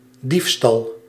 Ääntäminen
IPA: /di:f.stɑl/